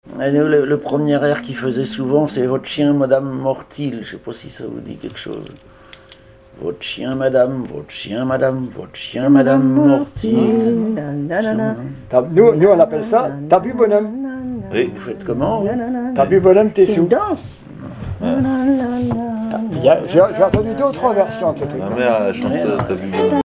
Laforte : Votre petit chien madame - V, F-159 Coirault : non-référencée - 141** Thème : 1074 - Chants brefs - A danser Résumé : Votre chien madame, votre chien Madame, Mord-t-il ?
Pièces instrumentales à plusieurs violons
Pièce musicale inédite